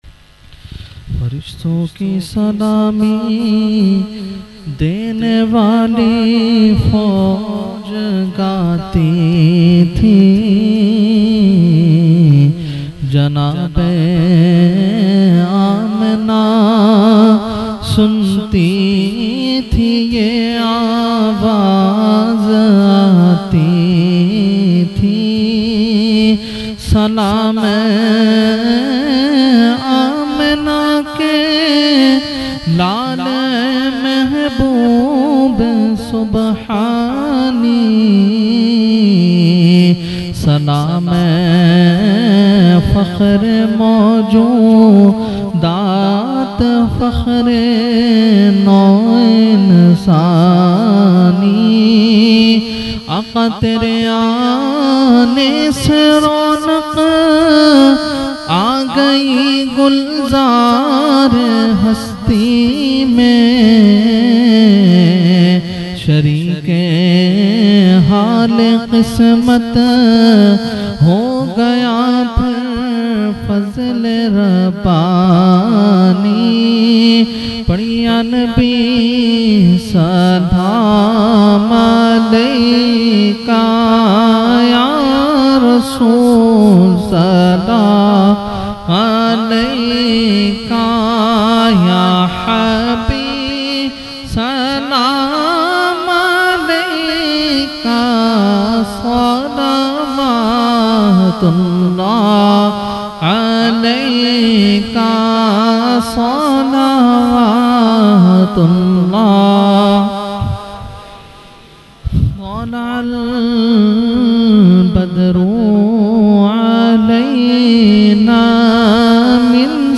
Mehfil e Jashne Subhe Baharan held on 28 September 2023 at Dargah Alia Ashrafia Ashrafabad Firdous Colony Gulbahar Karachi.
Category : Salam | Language : UrduEvent : Jashne Subah Baharan 2023